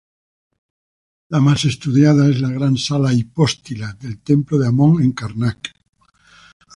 Pronúnciase como (IPA)
/ˈsala/